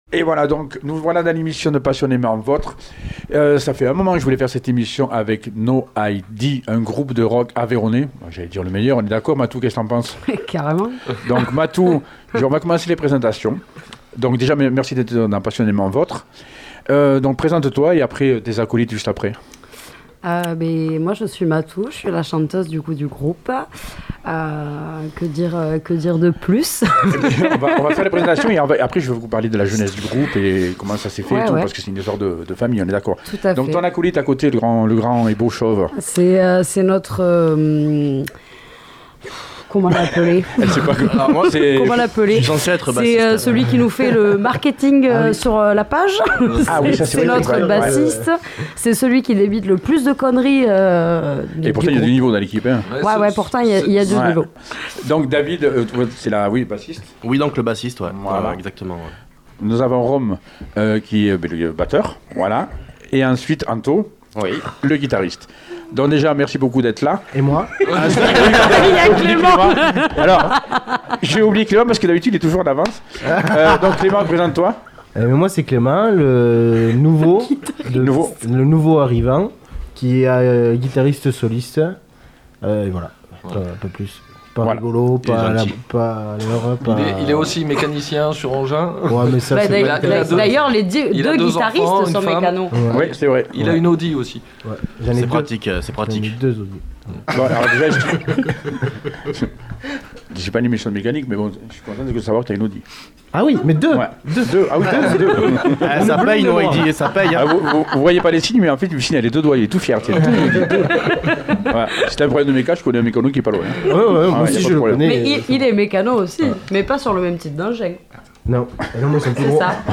Il a reçu le groupe Aveyronnais NOID. Ambiance survoltée ..fous rires...impro..solo de guitare endiable....Un moment mémorable à écouter et partager